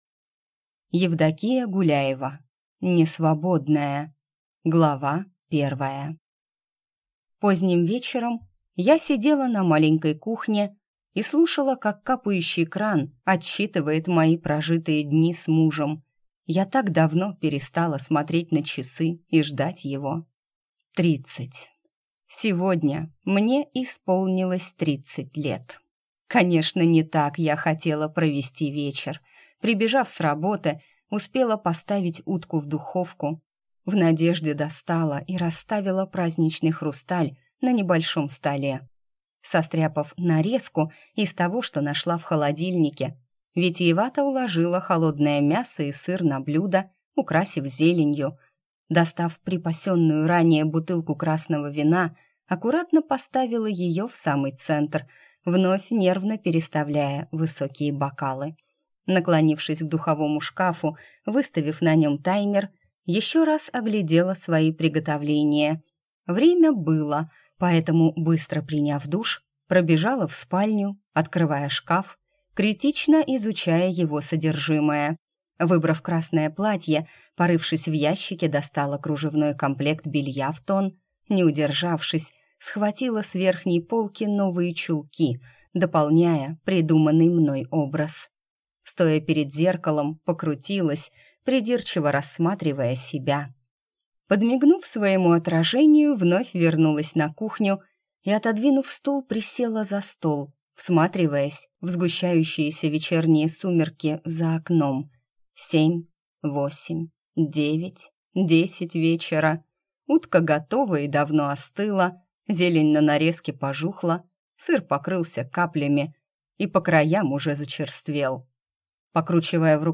Аудиокнига Несвободная | Библиотека аудиокниг